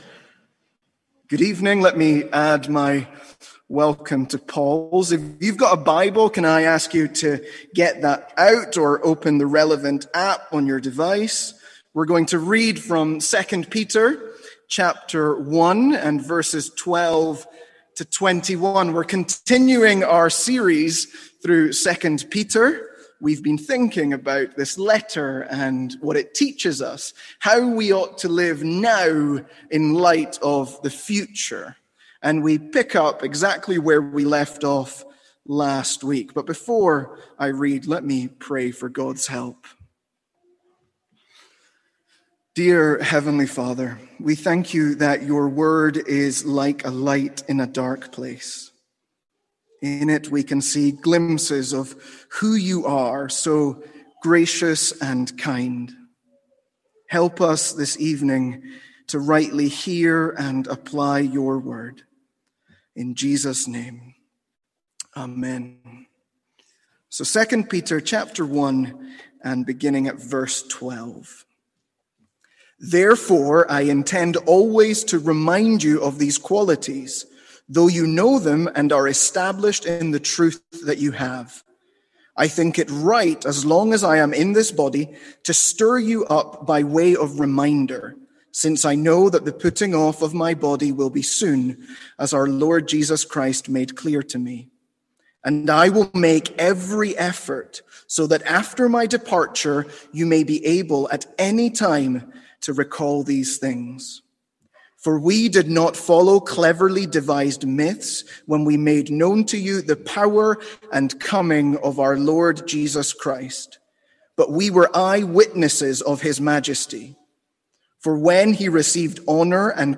Sermons | St Andrews Free Church
From our evening series in 2 Peter.